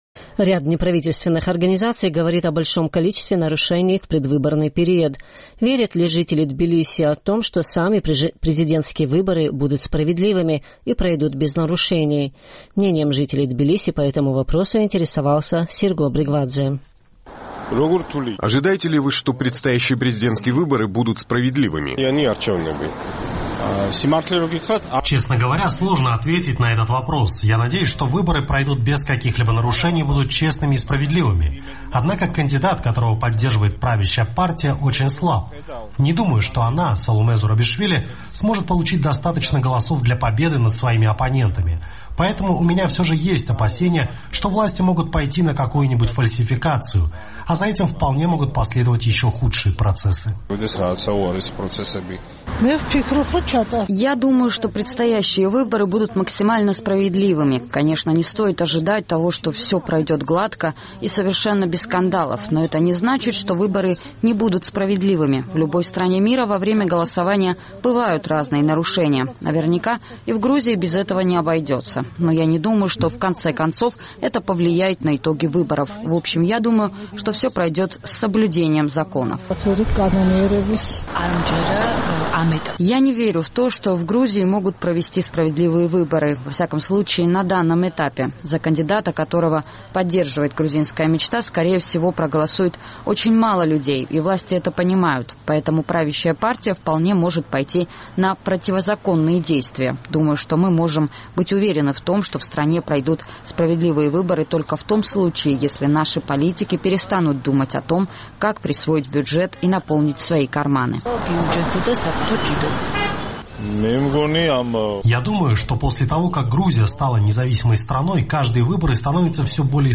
Ряд неправительственных организаций говорит о большом количестве нарушений в предвыборный период. Наш тбилисский корреспондент поинтересовался, верят ли жители столицы Грузии в то, что сами президентские выборы будут справедливыми и пройдут без нарушений.